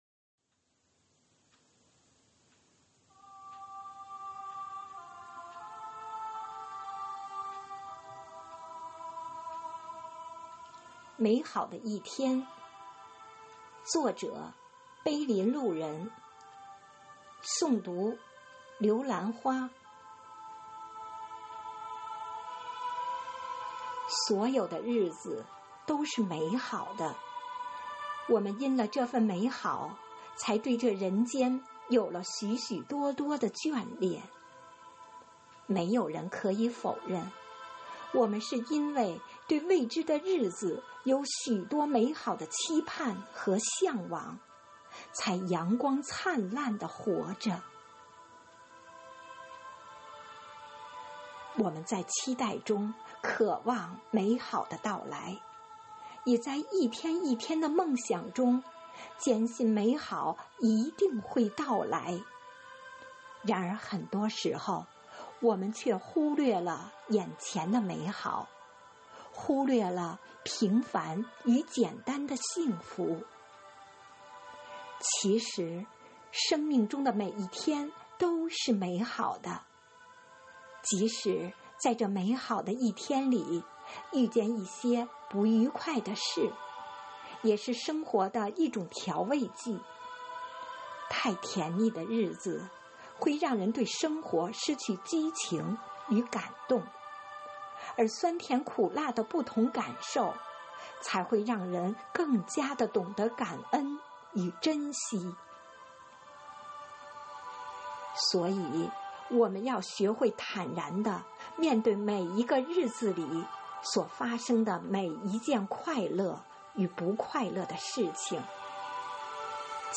“生活好课堂幸福志愿者广外科普大学朗读服务（支）队”是“生活好课堂幸福志愿者朗读服务队”的第二支队伍，简称“广外科普大学朗读支队”。“生活好课堂”的九岁生日当晚，全体队员演绎了一场激情澎湃的朗诵会。
《美好的一天》独诵